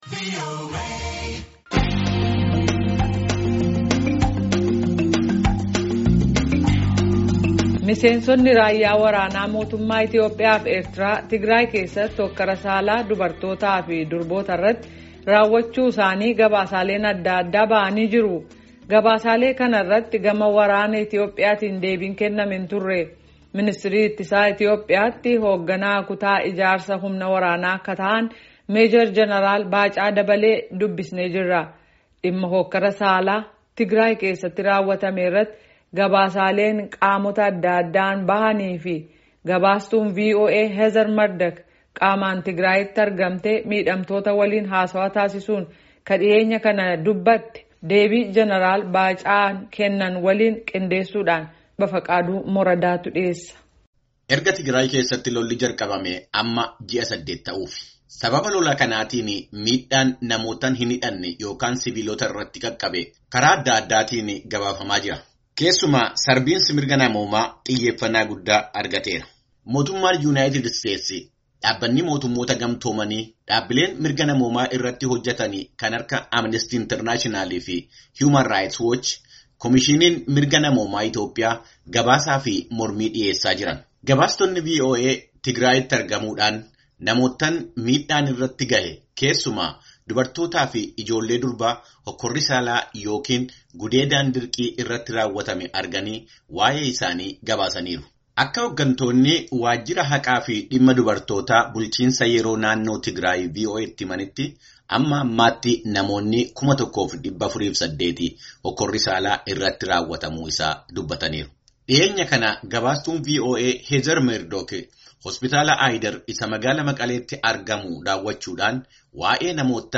Gaafii fi Deebii, Meejer Jeneraal Baacaa Dabalee Wajjin